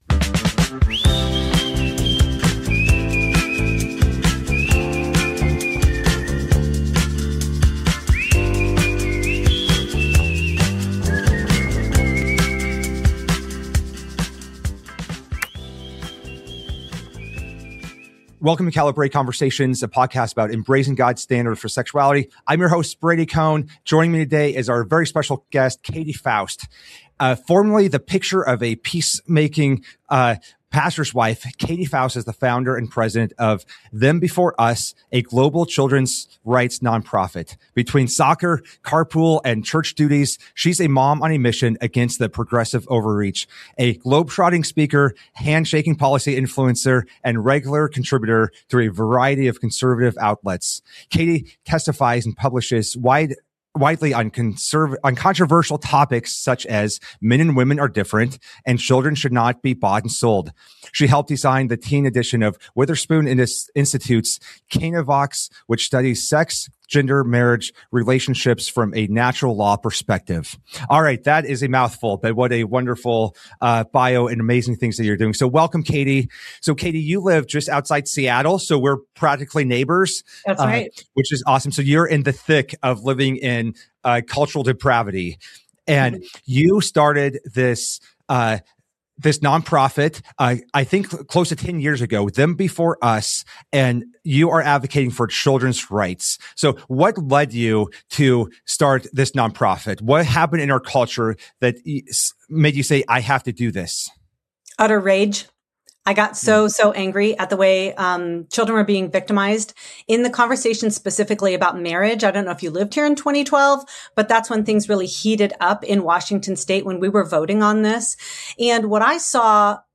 Calibrate Conversations